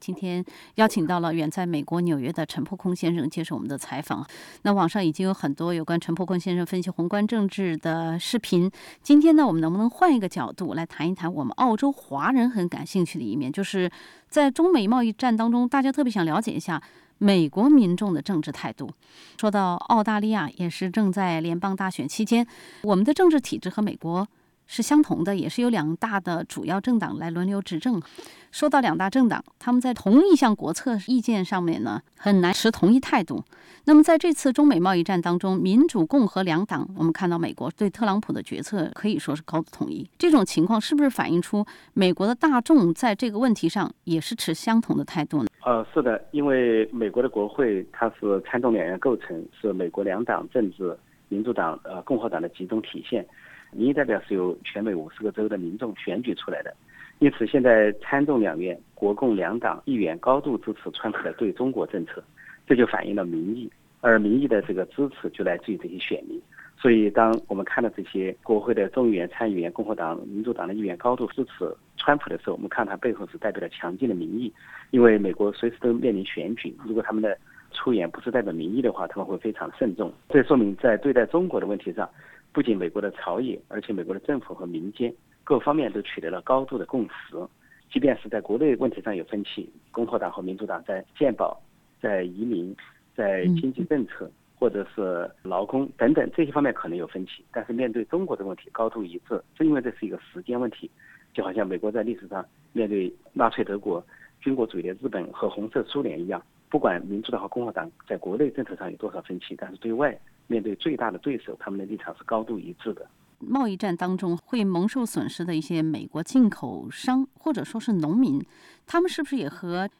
欢迎收听SBS普通话节目对旅美政治评论家、资深民运人士陈破空的采访。